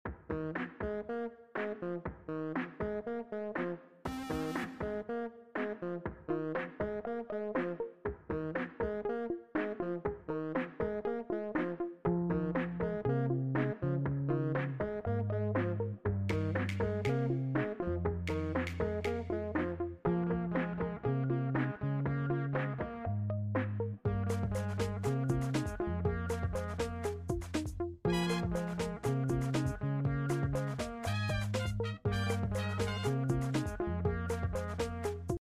woah sound effects free download